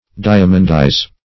diamondize - definition of diamondize - synonyms, pronunciation, spelling from Free Dictionary
Search Result for " diamondize" : The Collaborative International Dictionary of English v.0.48: Diamondize \Di"a*mond*ize\, v. t. To set with diamonds; to adorn; to enrich.